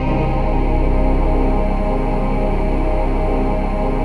VOICEPAD15-LR.wav